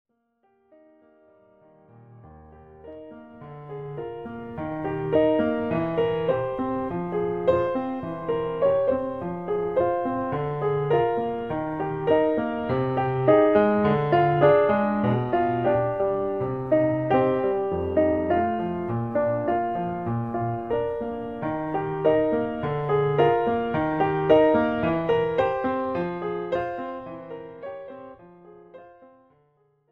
Classical and Opera